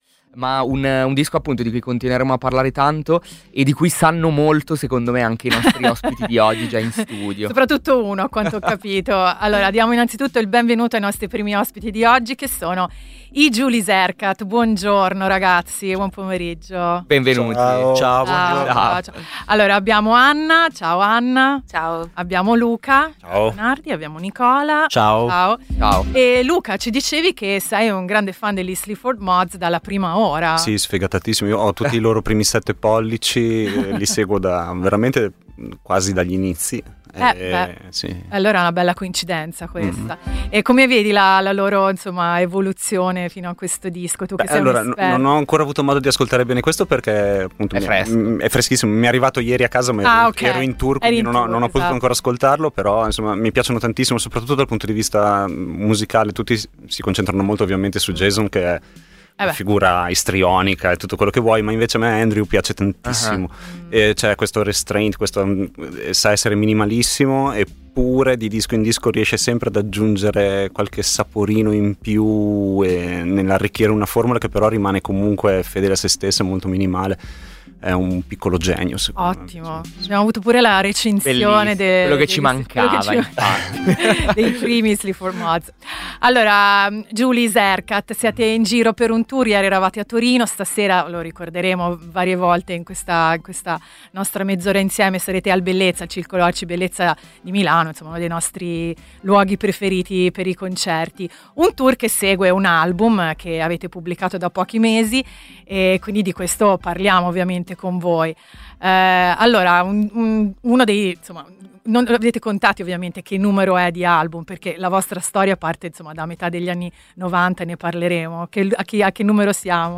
L'intervista